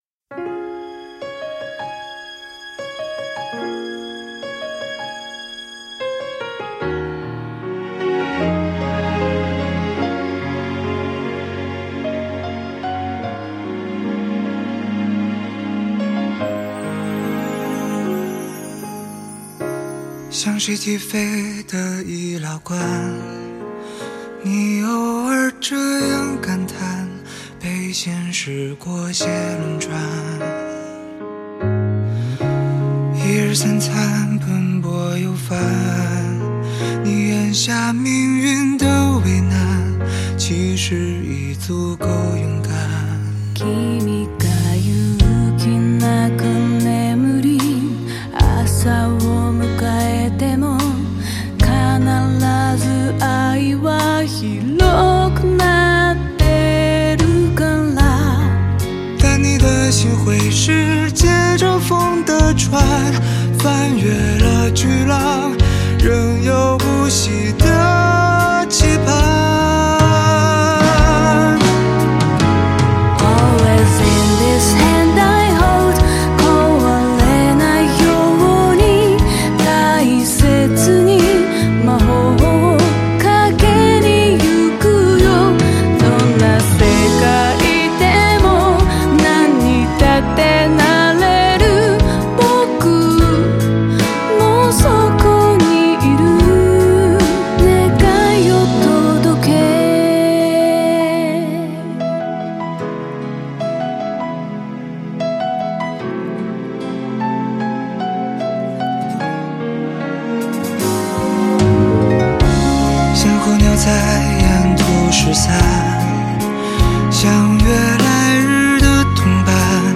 无可挑剔的人声发烧极品，多谢分享
兩人合唱很好聽